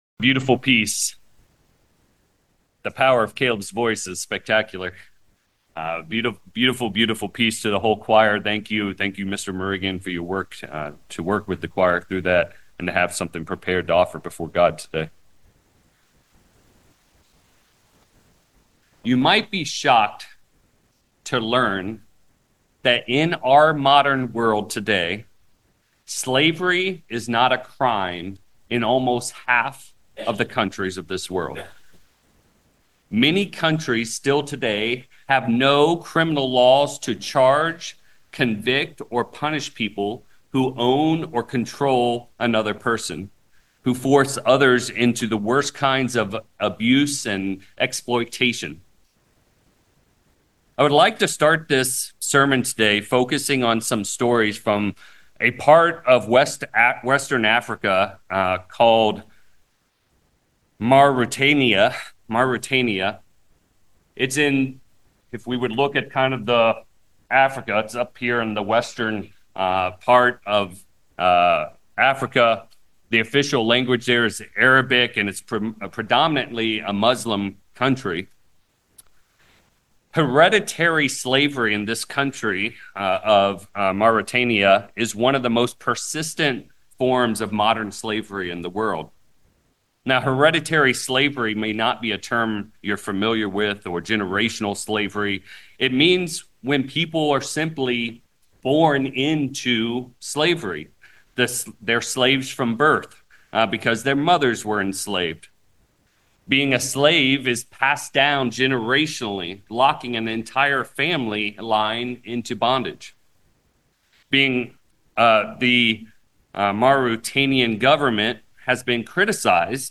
Generational slavery still occurs today in our modern world. In this sermon, we take a look at the impact hereditary slavery has on people, and then we explore how this topic applies not only to the nation of ancient Israel but also for us as Christians today.